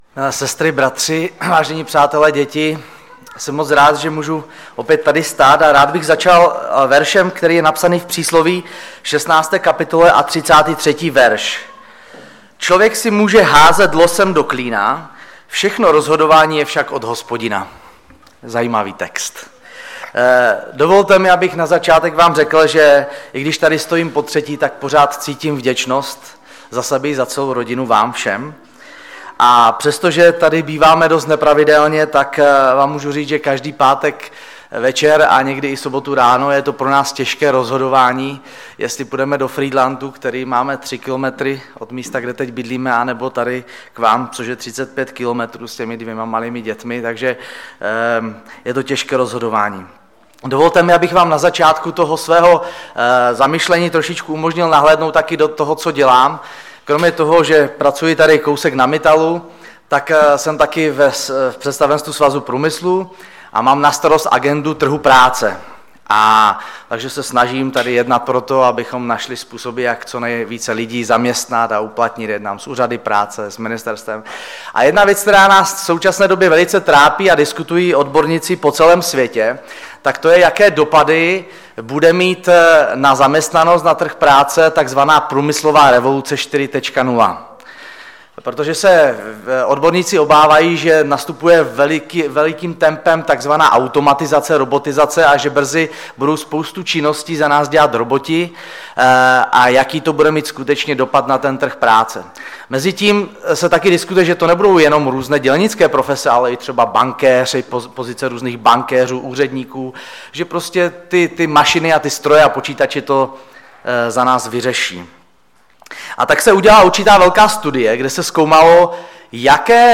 Kázání
Kazatel